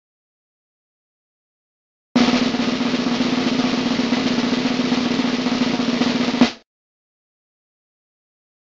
Drum roll